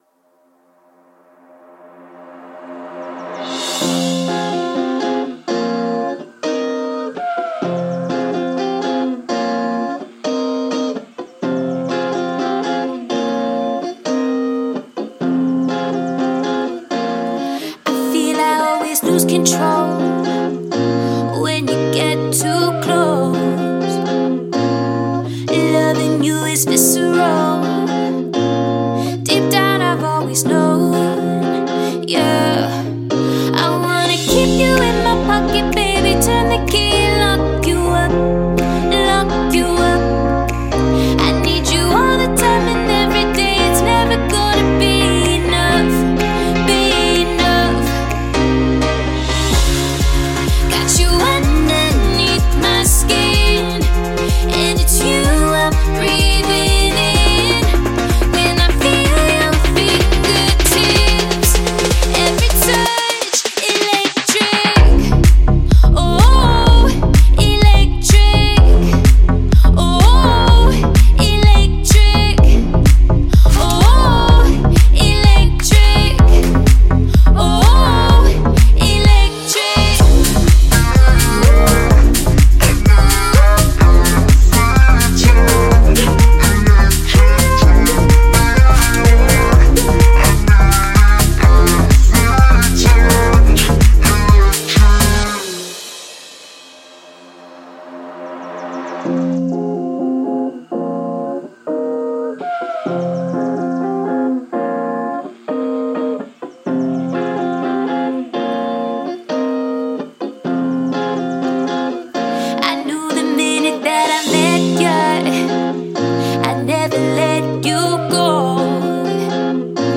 Электроника
Жанр: Жанры / Электроника